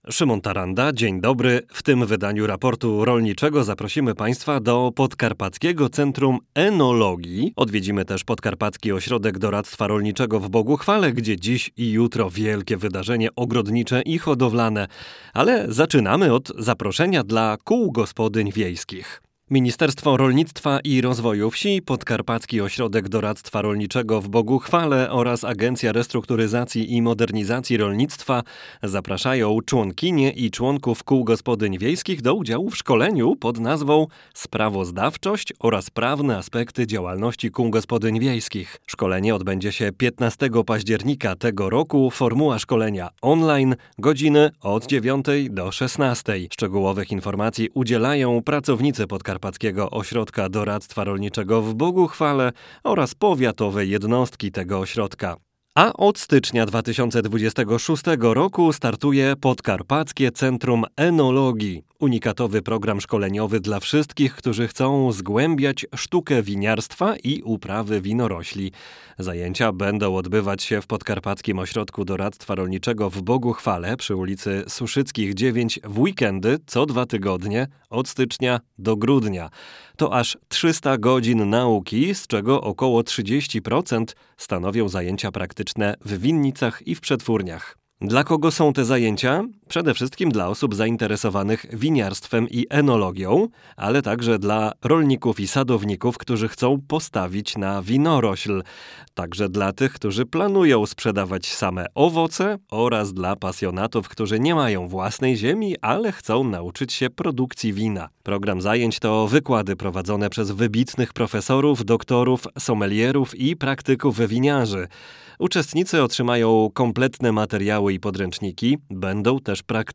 wysłuchania rozmowy